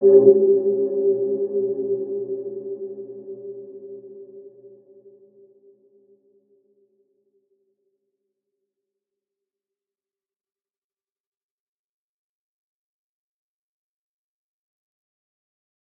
Dark-Soft-Impact-G4-p.wav